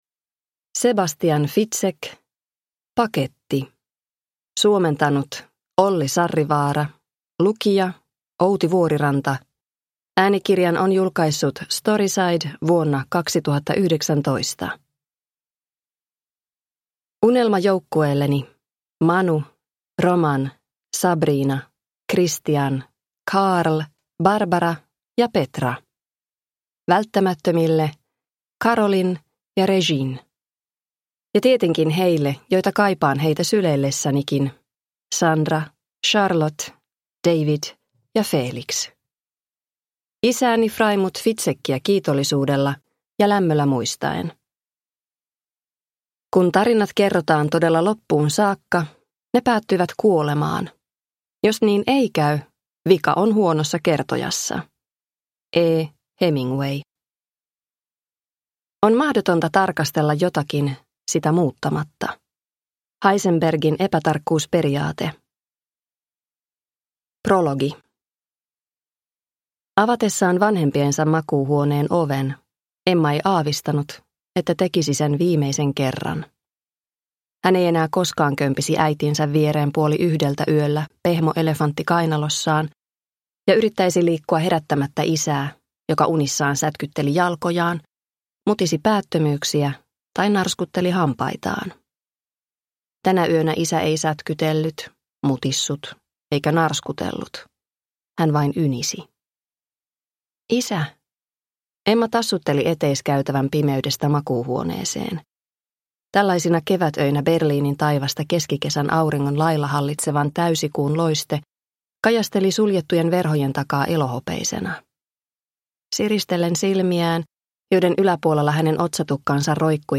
Paketti (ljudbok) av Sebastian Fitzek